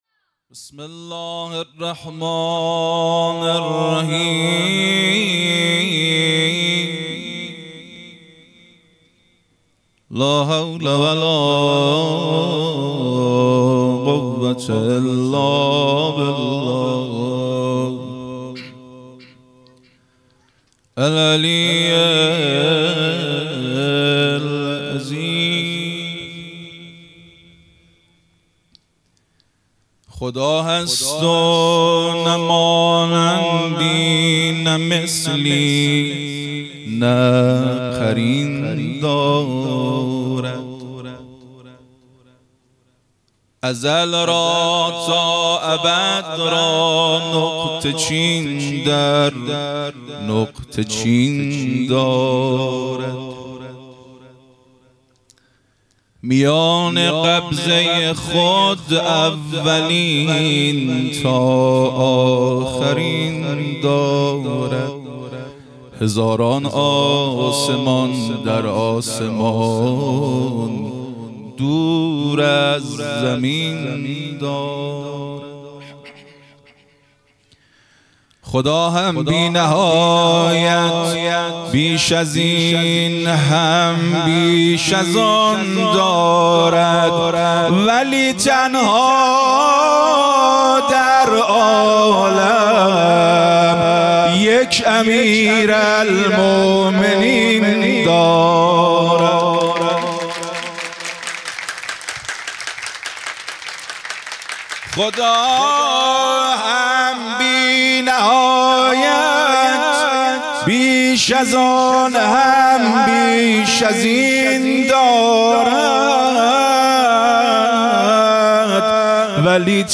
خیمه گاه - هیئت مکتب الزهرا(س)دارالعباده یزد - مدح | خدا هست و نه مانندی نه مثلی